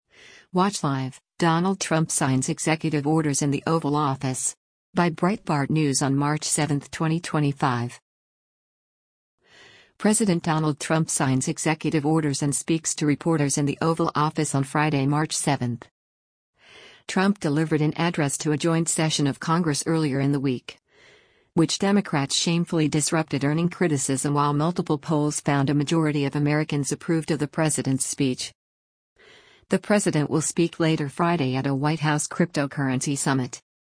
President Donald Trump signs executive orders and speaks to reporters in the Oval Office on Friday, March 7.